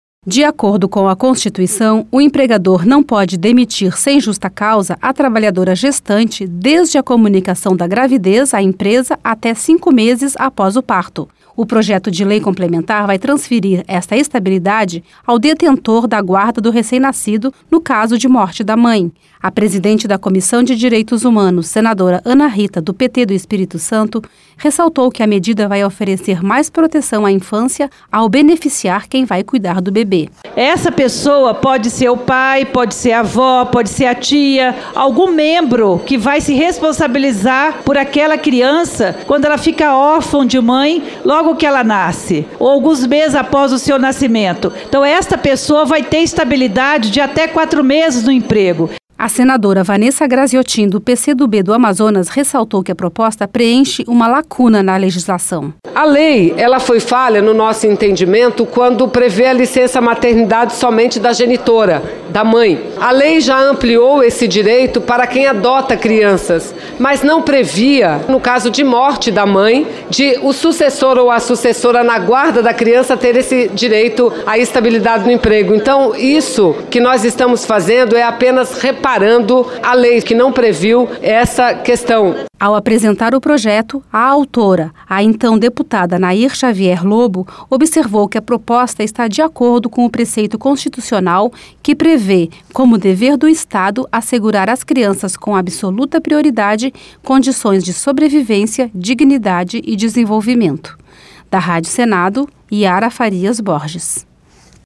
Rádio Senado
(Repórter)